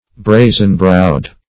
Brazen-browed \Bra"zen-browed`\, a. Shamelessly impudent.
brazen-browed.mp3